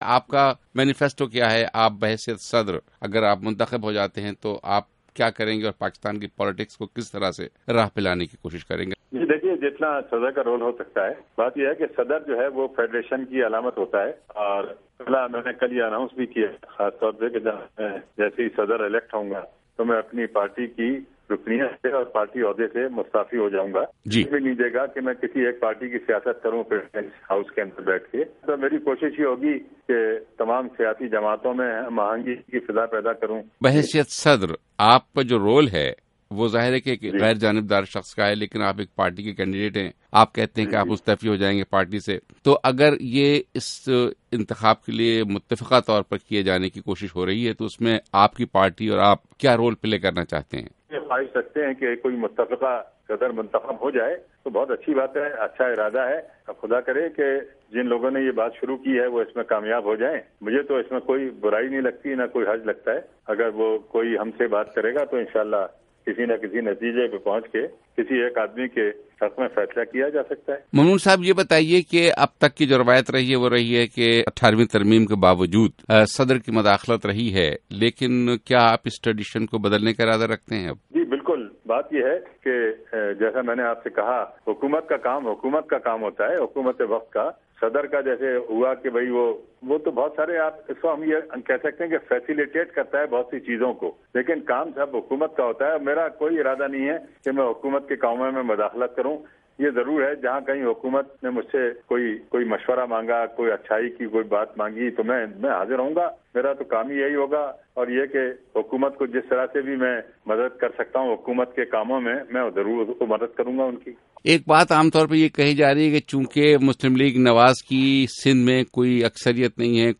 پی ایم ایل (ن) کے صدارتی امیدوار، ممنون حسین کا انٹرویو